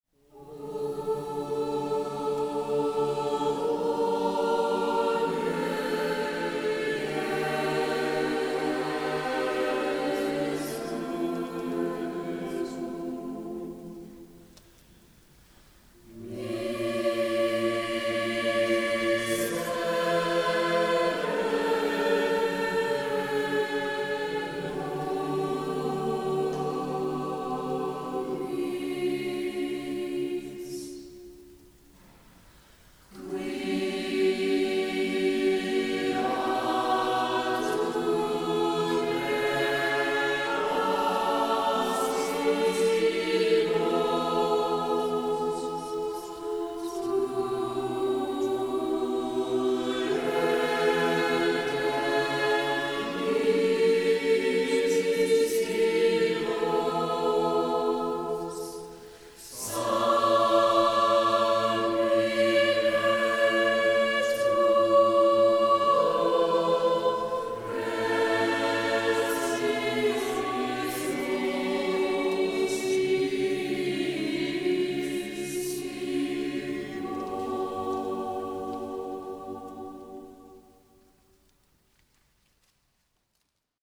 O CELEBRATE THE 500TH YEAR of Giovanni Pierluigi da Palestrina, our parish choirs came together for a special concert honoring one of the greatest composers in the history of sacred music.
O Bone Jesu (SATB)
A more expressive and chromatic piece than Palestrina’s typical motets, O Bone Jesu stands out for its emotional intensity.
Live recording • O Bone Jesu